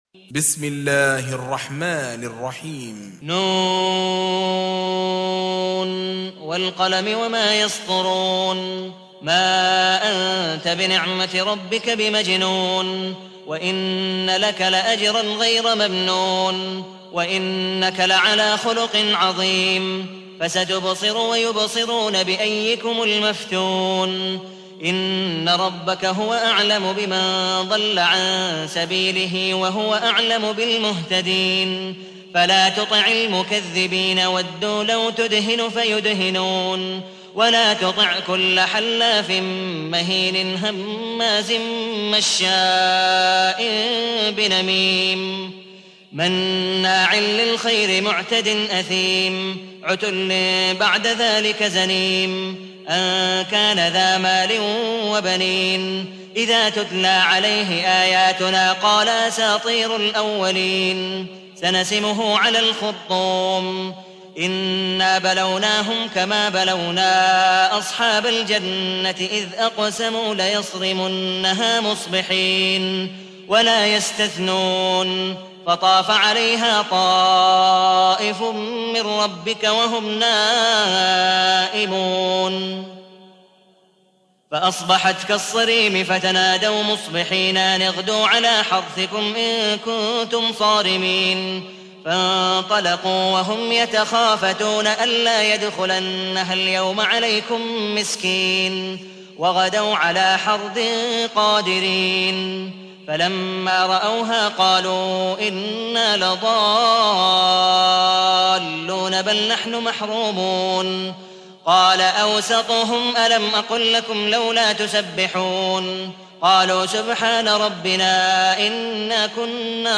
تحميل : 68. سورة القلم / القارئ عبد الودود مقبول حنيف / القرآن الكريم / موقع يا حسين